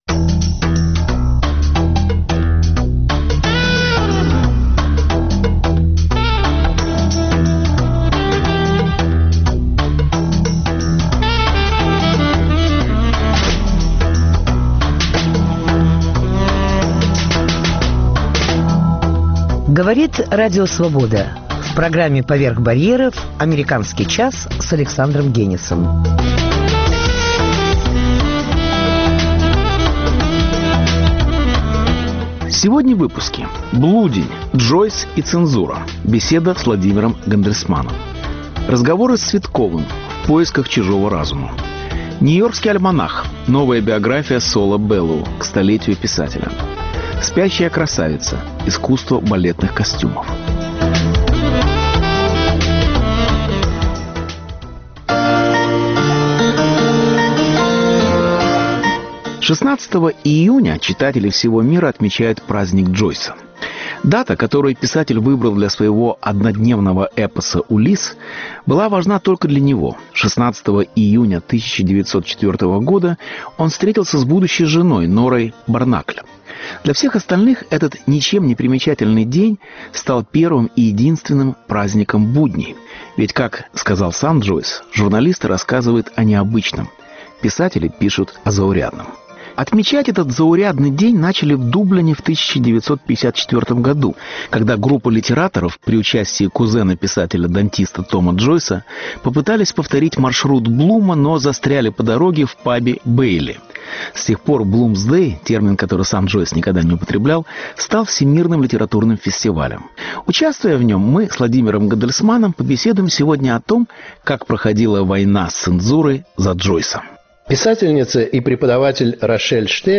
Блудень”: Джойс и цензура. Беседа с Владимиром Гандельсманом